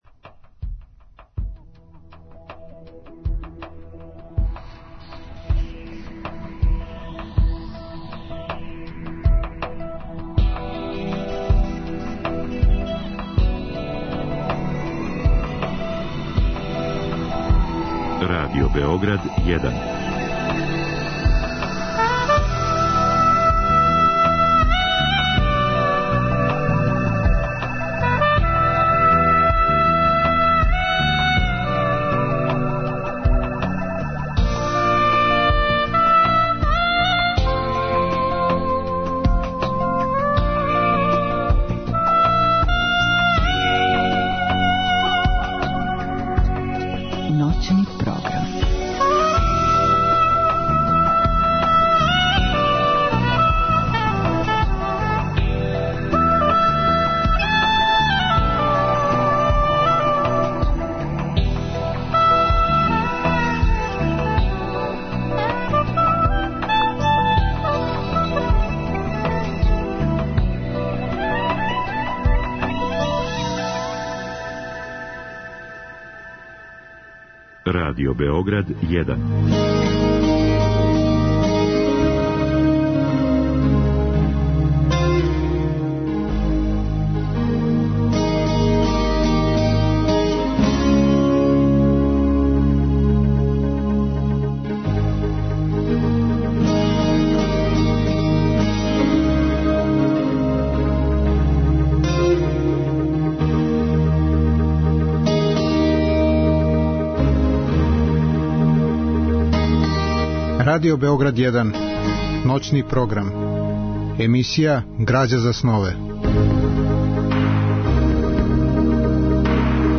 Разговор и добра музика требало би да кроз ову емисију и сами постану грађа за снове.